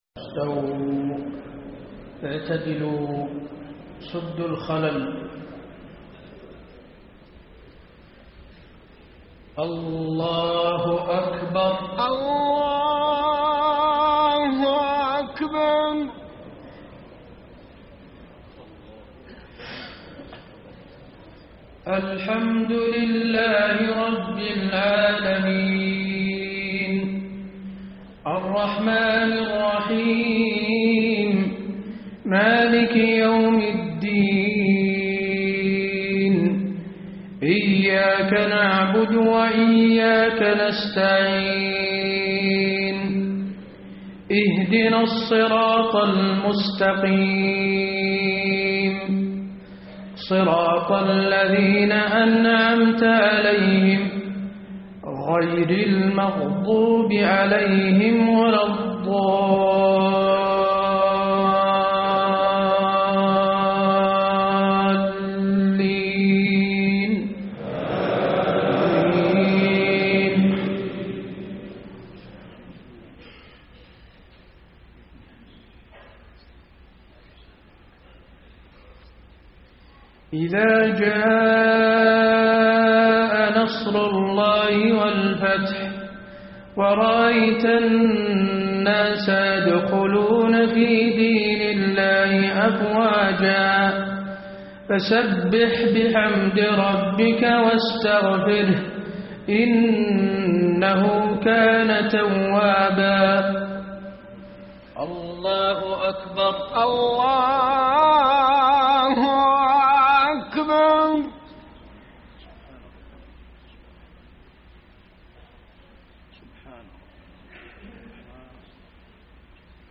صلاة المغرب 3-6-1434 من سورتي النصر و الإخلاص > 1434 🕌 > الفروض - تلاوات الحرمين